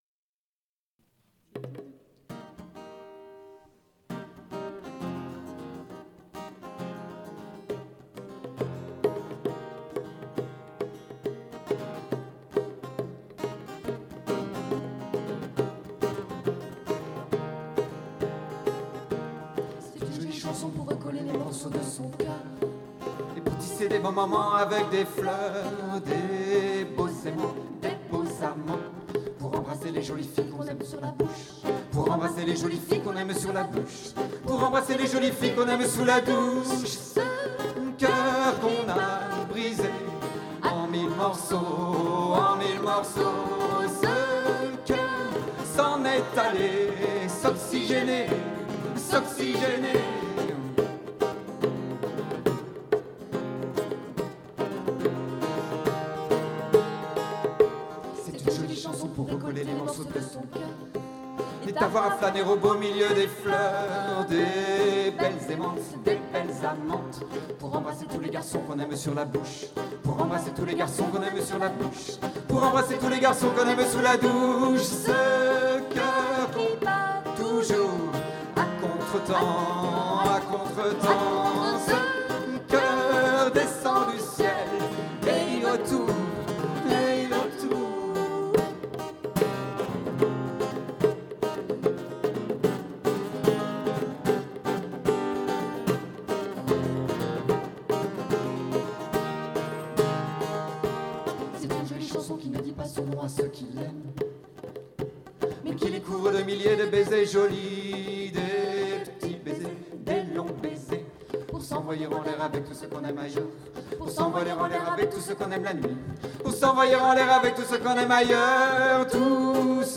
# extraits concert au pannonica, nantes, 22 avril 2006